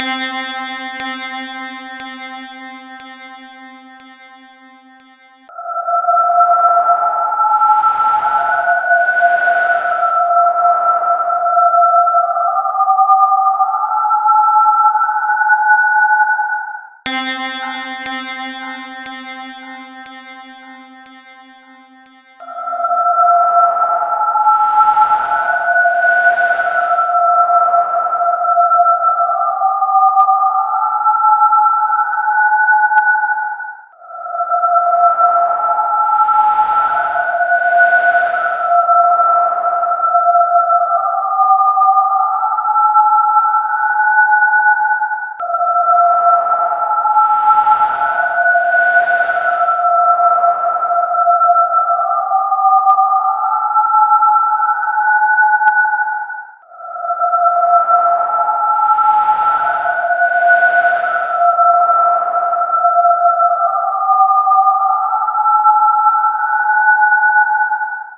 拉长的DTMF
描述：随机噪声转换为DTMF音调，然后拉伸或转换为带回声的啁啾声
标签： 拉伸 DTMF色调 持续
声道立体声